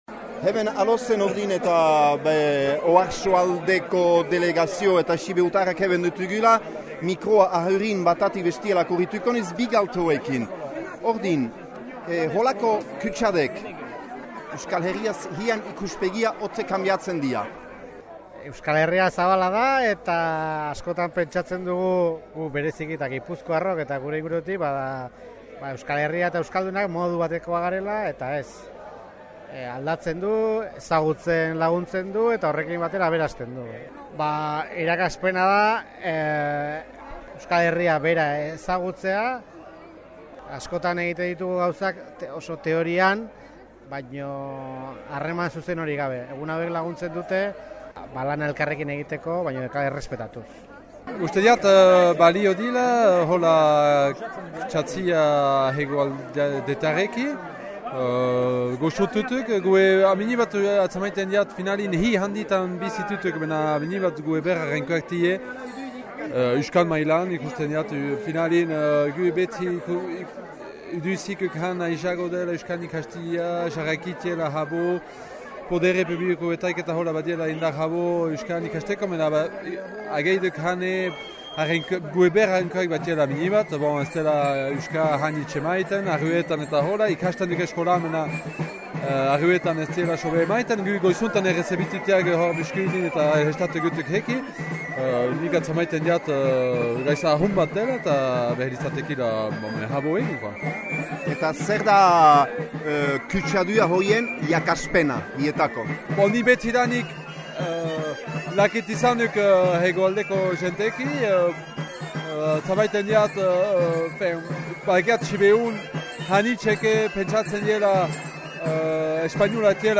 Entzün Zazpiak bat egünean ziren jente zonbait :